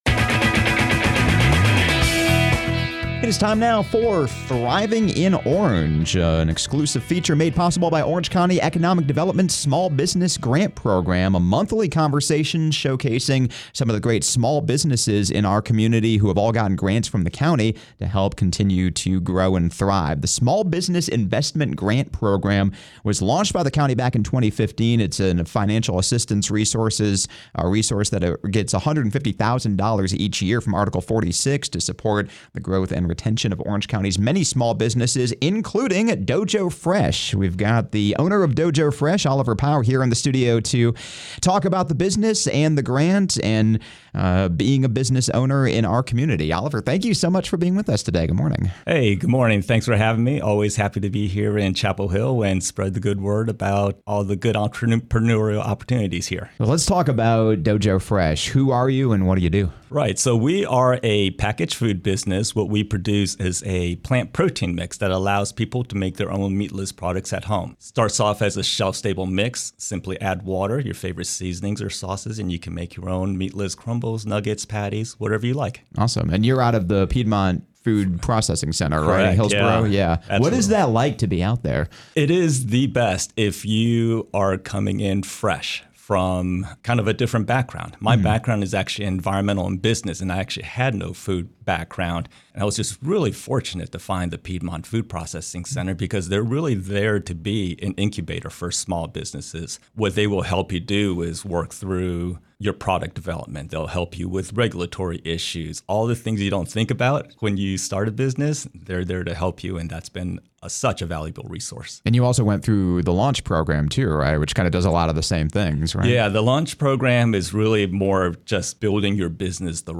A monthly conversation discussing Orange County’s small business grant program, which began in 2015 and provides $150,000 in small business support each year to local businesses.
You can find more conversations like this in the “Thriving in Orange” archive on Chapelboro, and each month in a special segment airing on 97.9 The Hill!